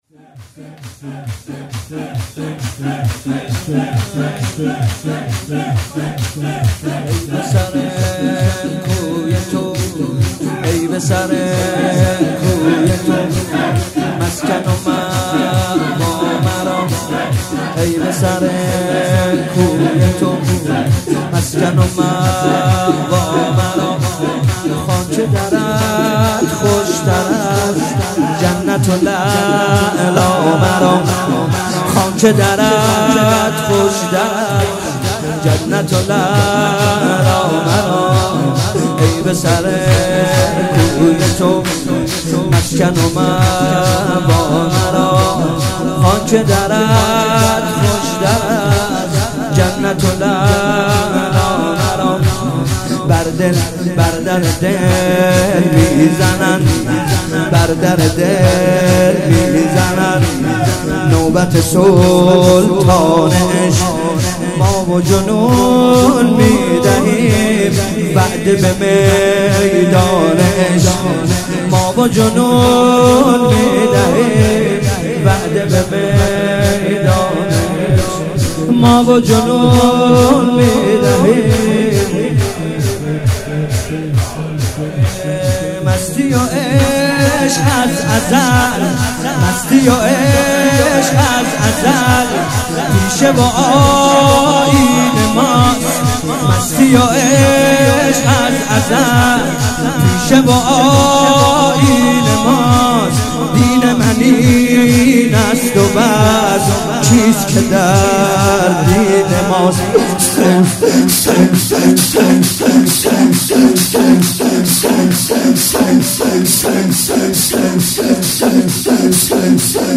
گلچین مداحی های ایام شهادت امام صادق(ع)